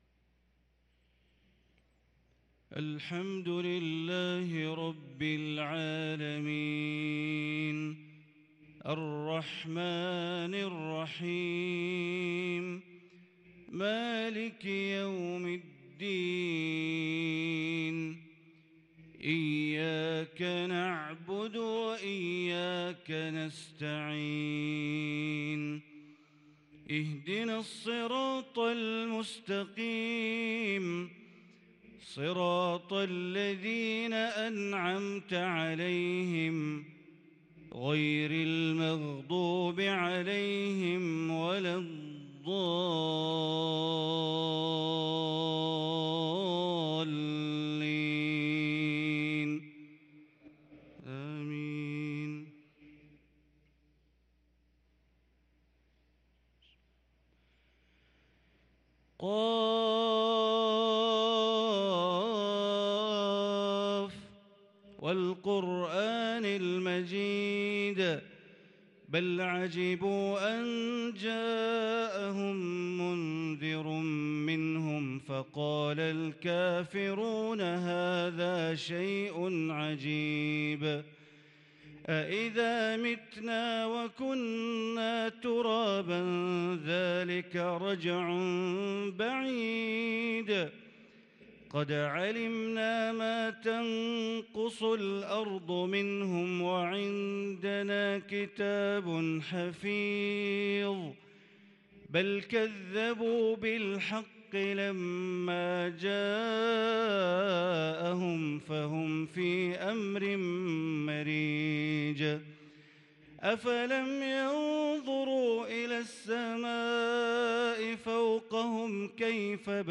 صلاة الفجر للقارئ بندر بليلة 11 ربيع الأول 1444 هـ
تِلَاوَات الْحَرَمَيْن .